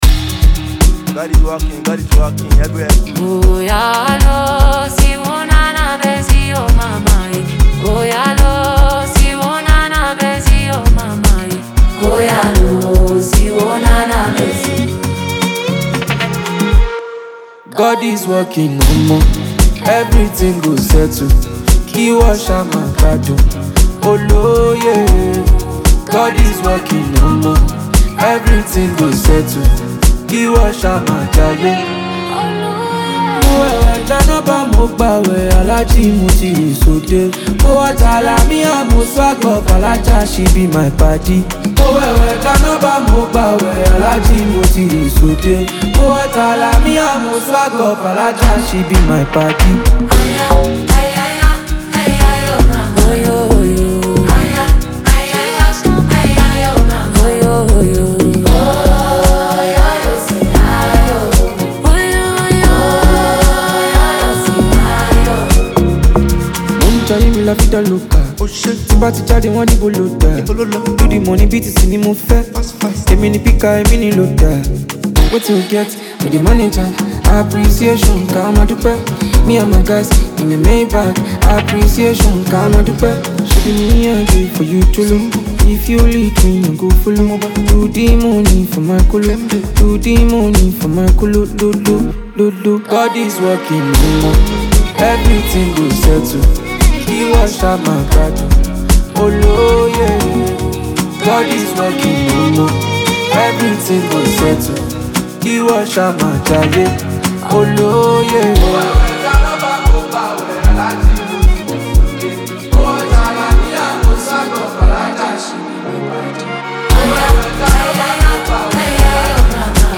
With smooth melodies and a captivating Afro-fusion vibe